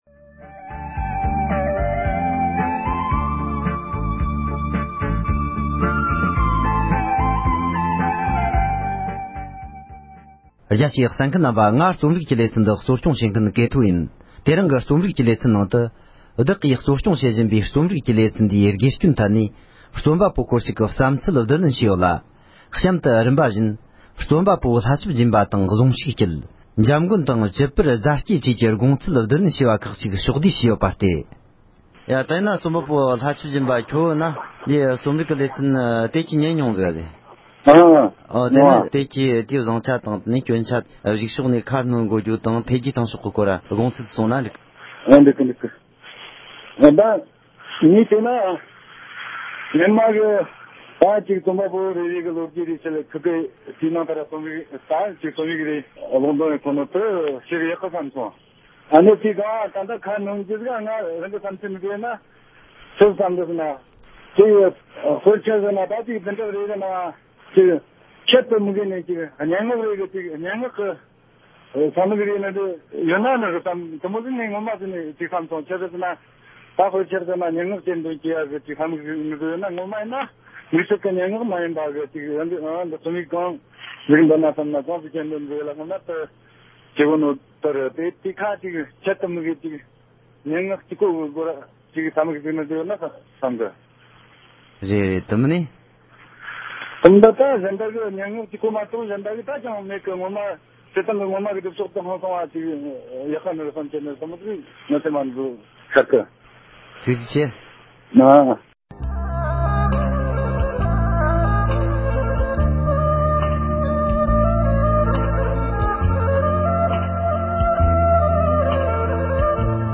རྩོམ་པ་པོ་སྐོར་ཞིག་གིས་རྩོམ་རིག་གི་དོ་ར་ཞེས་པའི་ལེ་ཚན་འདིའི་དགེ་སྐྱོན་སྐོར་གསུངས་བ།